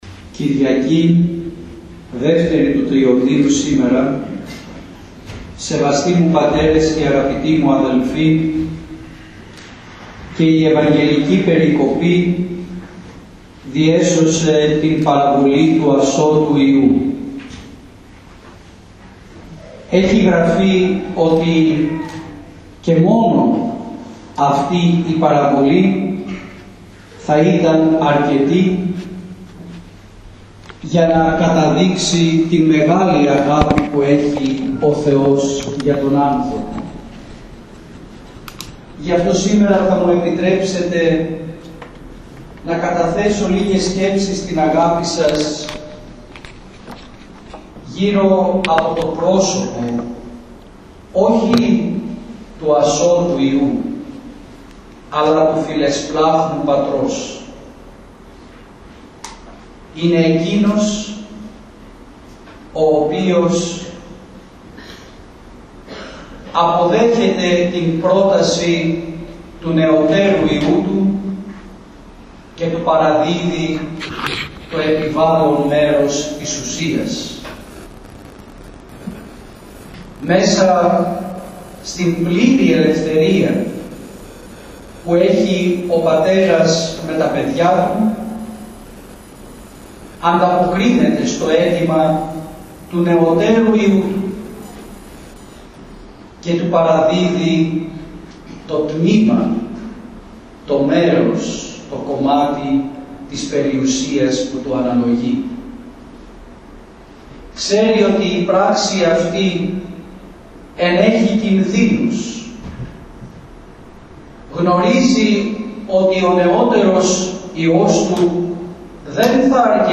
Ο Σεβασμιώτατος Μητροπολίτης Αιτωλίας και Ακαρνανίας κ. Δαμασκηνός, τιμώντας την μνήμη του Προκατόχου του, ιερούργησε στην γυναικεία Ιερά Μονή Μεταμορφώσεως του Σωτήρος Παντοκράτορος Αγγελοκάστρου και τέλεσε το Ιερό Μνημόσυνο του αειμνήστου Αρχιερέως, παρουσία πνευματικών του τέκνων και στενών συνεργατών του, κληρικών και λαϊκών.